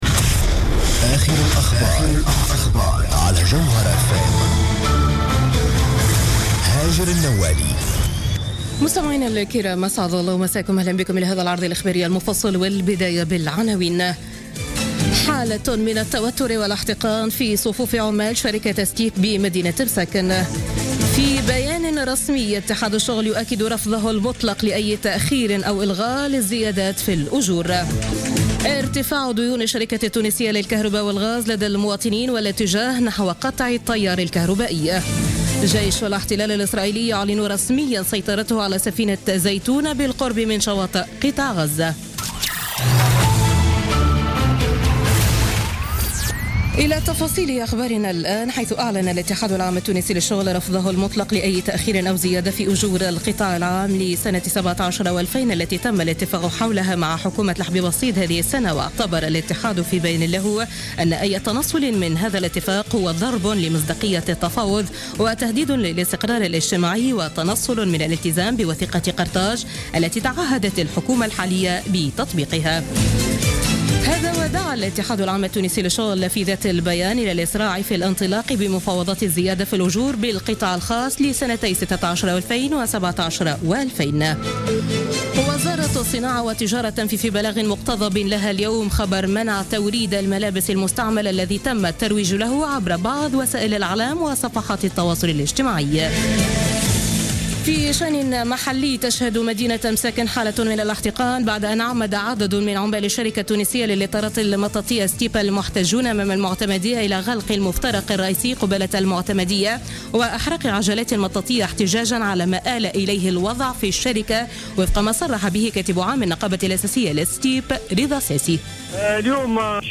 نشرة أخبار السابعة مساء ليوم الأربعاء 5 أكتوبر 2016